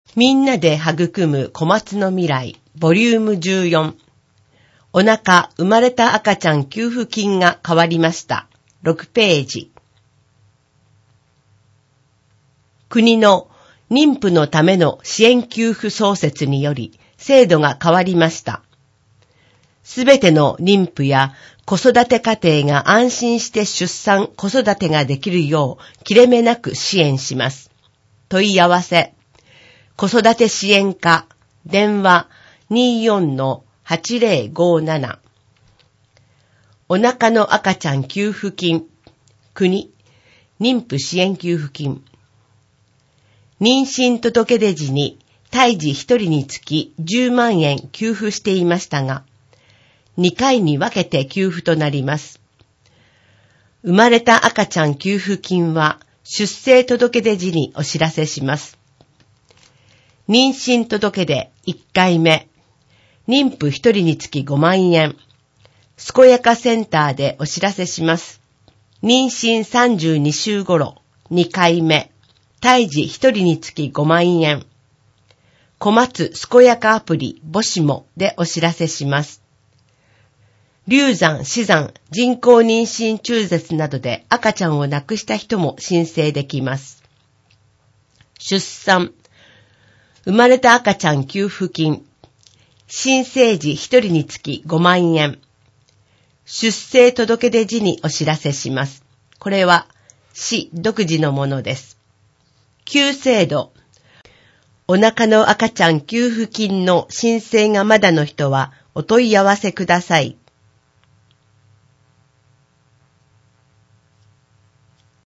広報こまつの音訳は音訳ボランティアグループ「陽だまり会」の皆さんの協力で行っています。